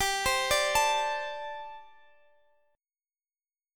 Listen to Gsus2sus4 strummed